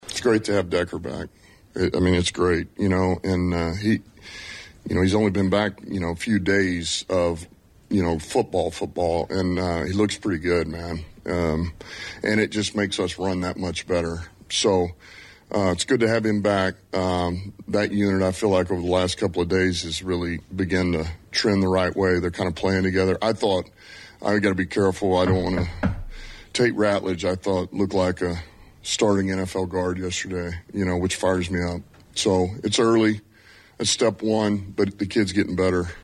Detroit Lions head coach Dan Campbell met with reporters Thursday ahead of the teams’ joint practice with the Miami Dolphins.